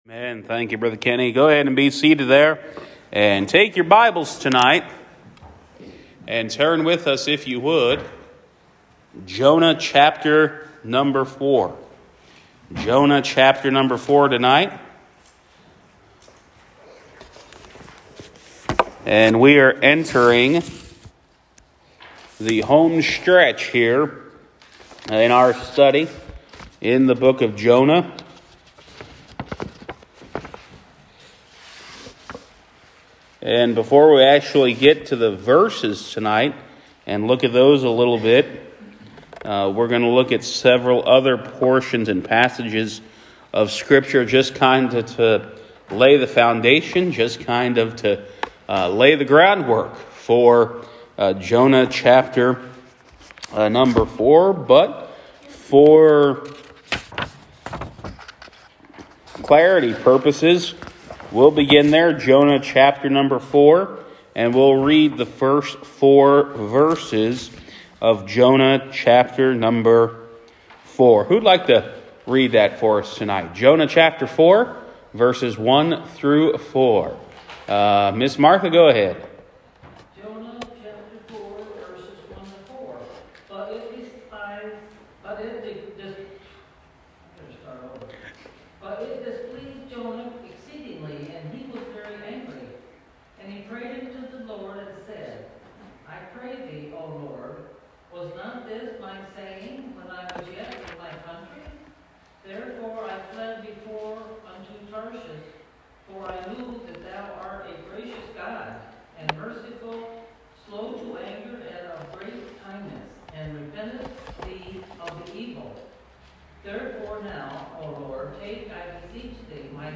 Wednesday Evening Bible Study